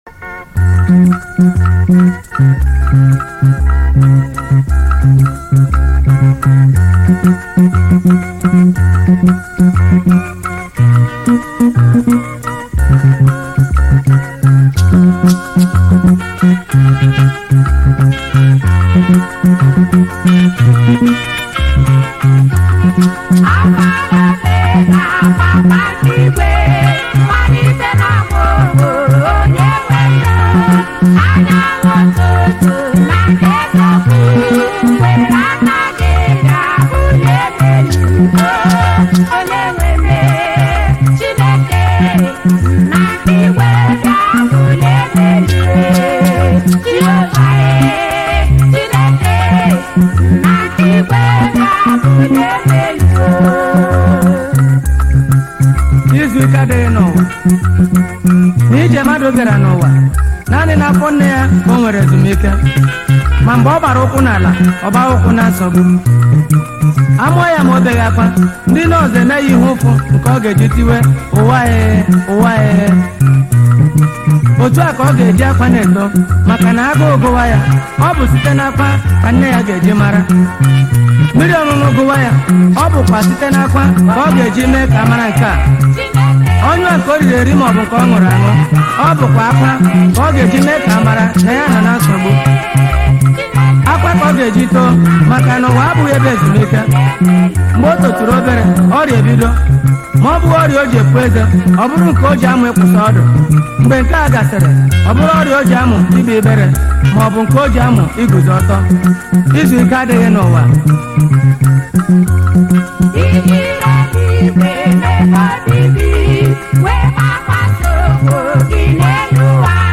February 21, 2025 Publisher 01 Gospel 0